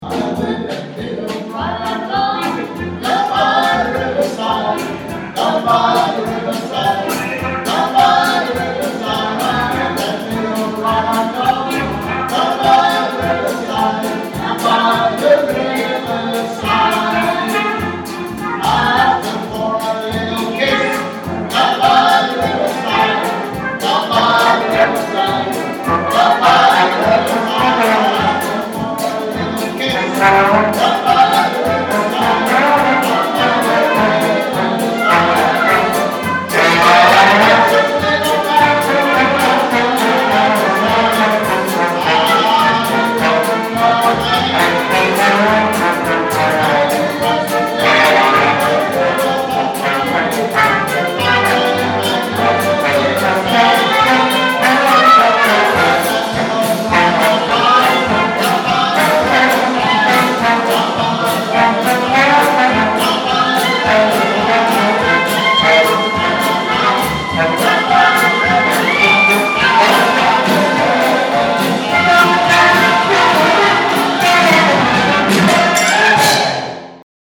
Jazz Bands
Duo jazz to Big Bands